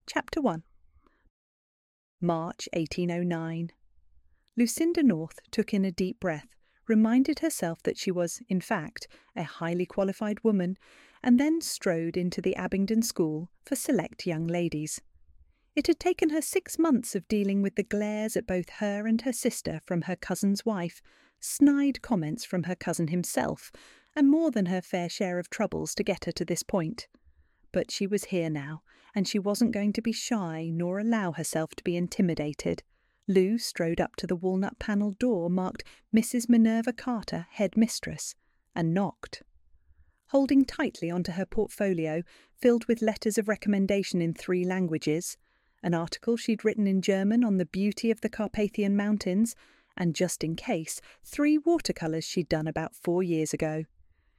This has one voice:
In-Lieu-One-Voice-Sample.mp3